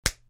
На этой странице представлены звуки подзатыльника – резкие, неожиданные аудиоэффекты.
Подзатыльник – услышь это